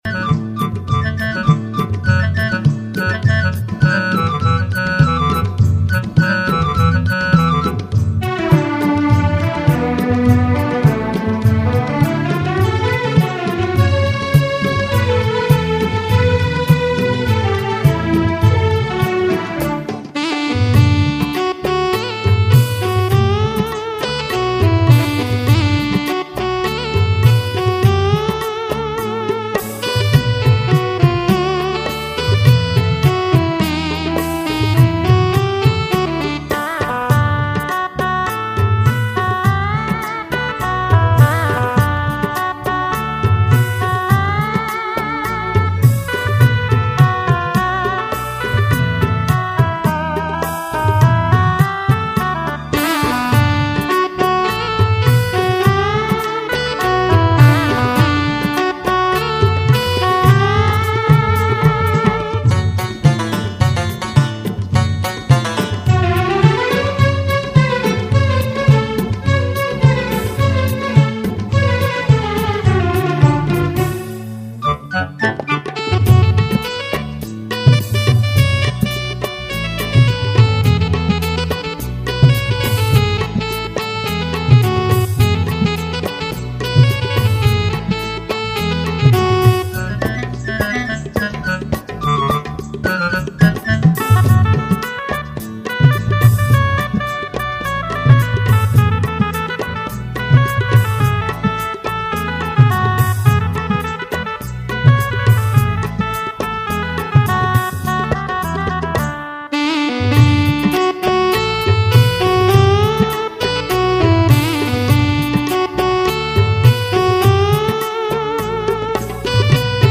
Sinhala Instrumental Mp3 Download